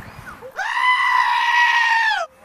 Crazy Goat Scream